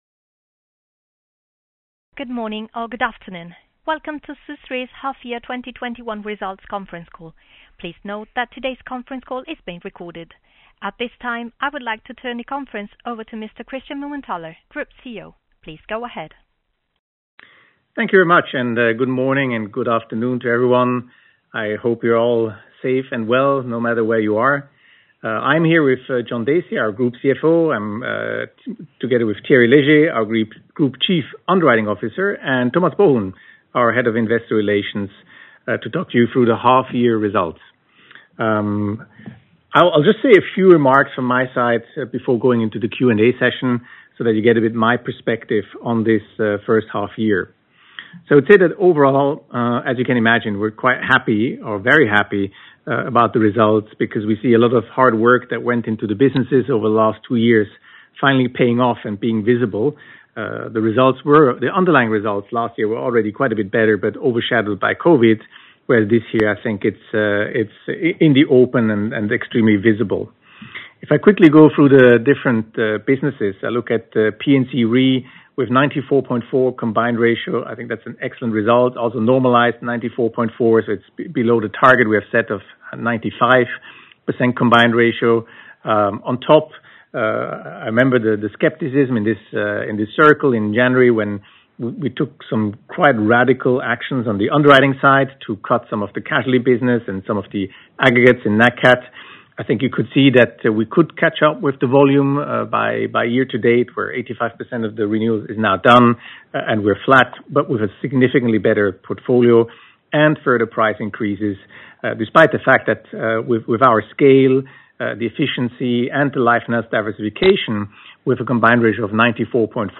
hy-2021-call-recording.mp3